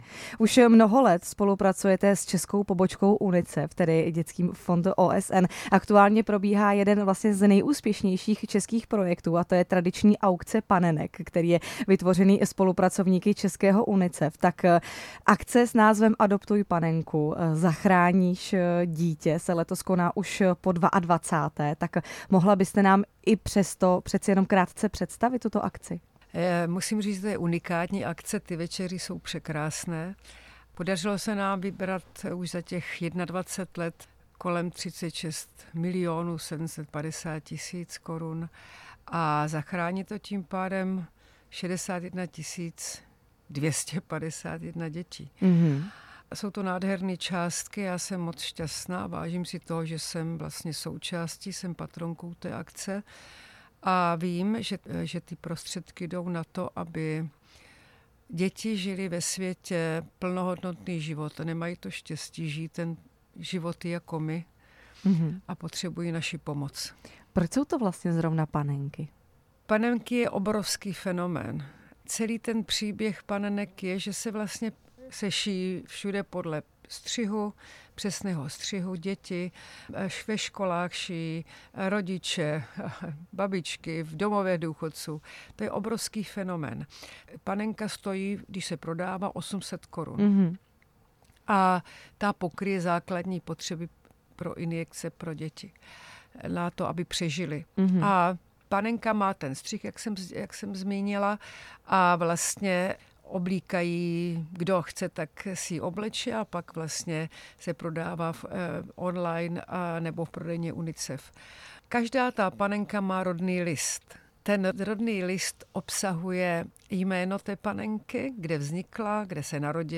Liběna Rochová ve vysílání Radia Prostor